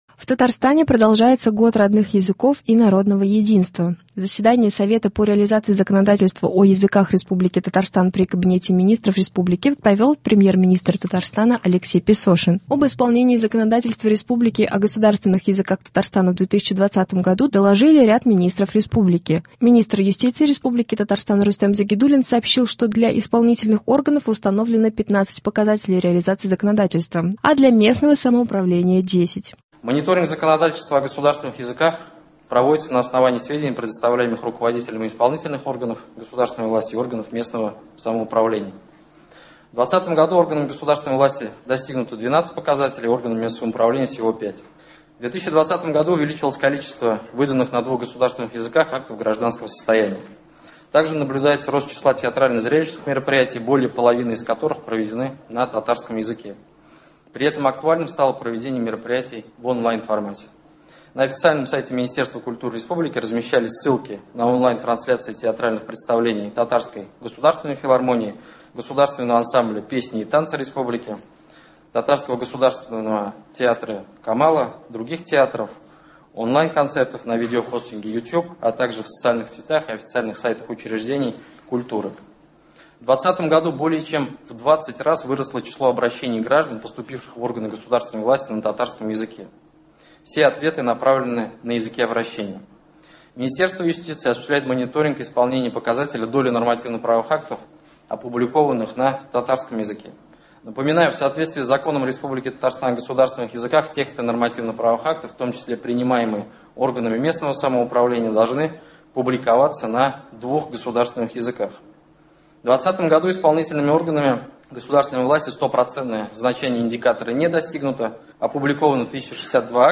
Репортаж с заседания Совета по реализации законодательства о языках Республики Татарстан при Кабинете Министров РТ .